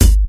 • '00s Rich Highs Hip-Hop Bass Drum Single Hit G Key 36.wav
Royality free steel kick drum tuned to the G note. Loudest frequency: 1001Hz